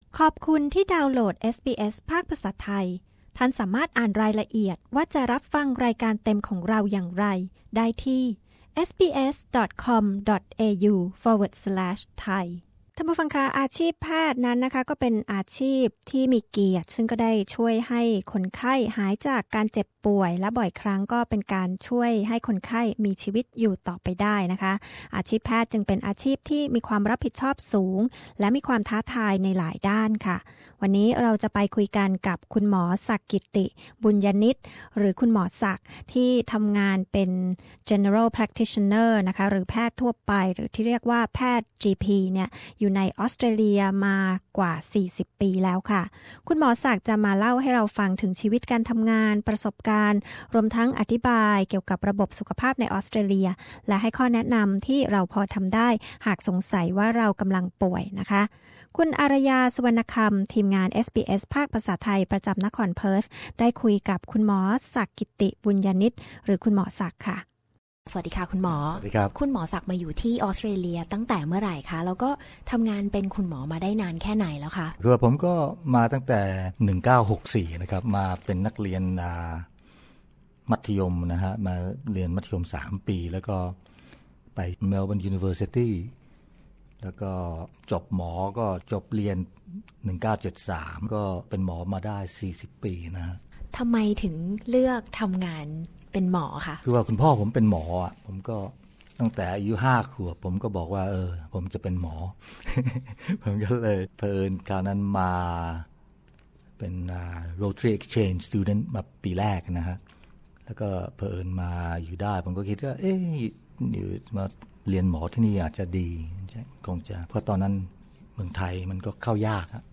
A Chat with Thai Doctor in Australia